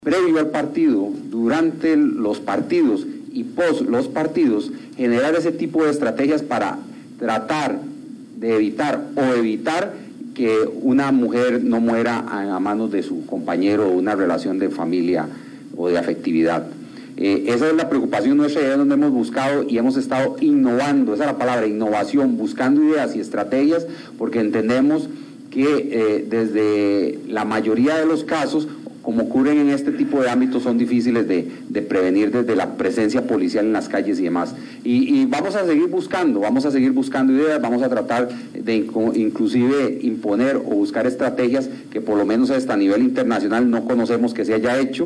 Además, el ministro de seguridad, Michael Soto, manifestó que han estado buscando ideas para combatir los episodios de violencia que se producen durante un partido de fútbol.